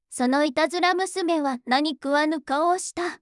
voicevox-voice-corpus / ita-corpus /もち子さん_怒り /EMOTION100_013.wav